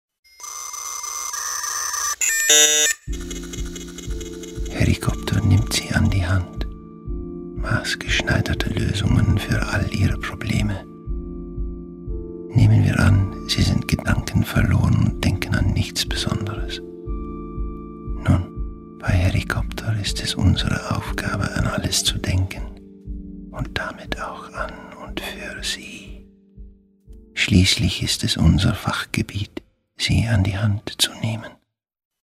Minihörspiele, die für abwegige Gedanken, neue Wahrnehmungen und intelligenten Humor werben.
Archiv Wurfsendungs-Serie Herri Kopter 00:32 Minuten Hypnotisch wie die Herri-Kopter-Stimme © EyeEm